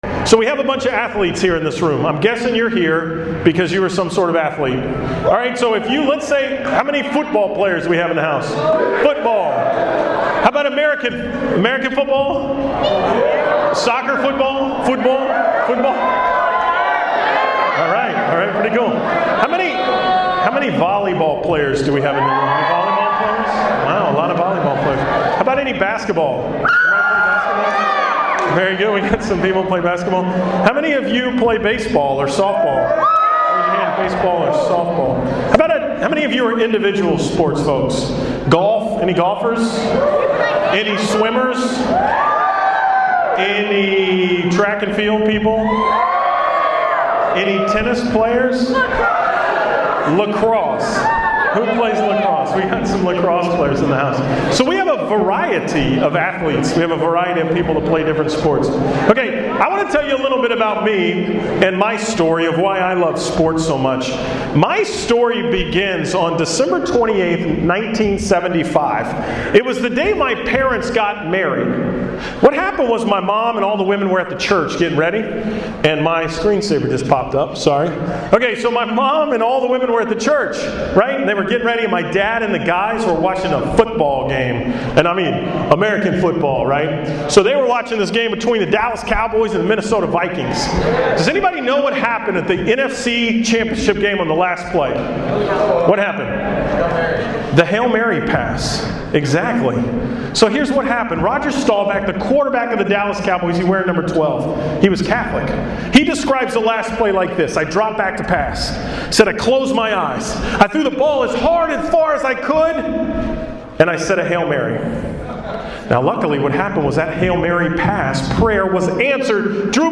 The talk I gave at the 2013 Archdiocesan Youth Conference.
ayc-talk-2013.mp3